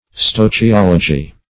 Stoechiology \Stoech`i*ol"o*gy\, n., Stoechiometry